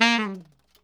TNR SHFL A#3.wav